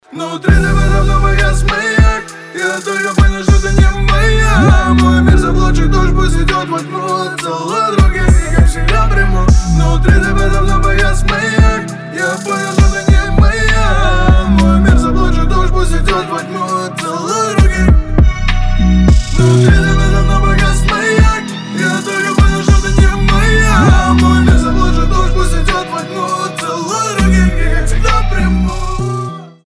• Качество: 320, Stereo
лирика
медленные